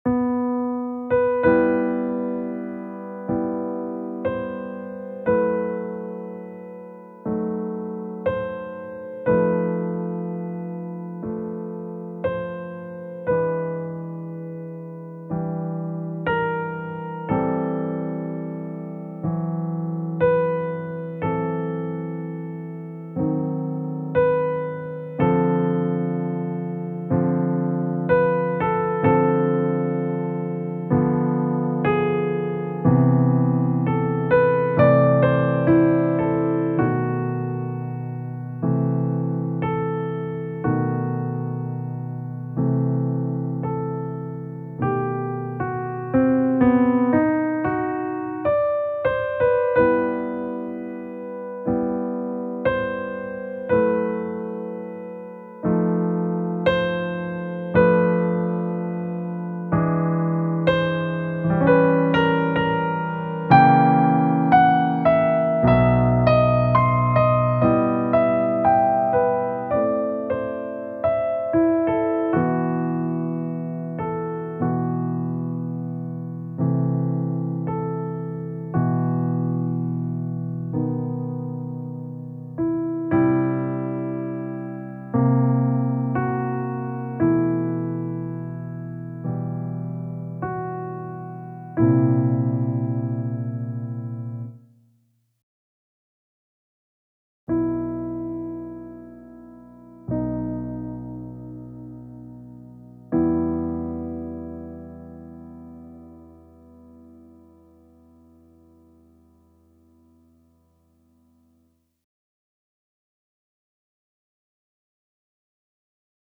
contexto histórico y grabaciones de referencia incluidas.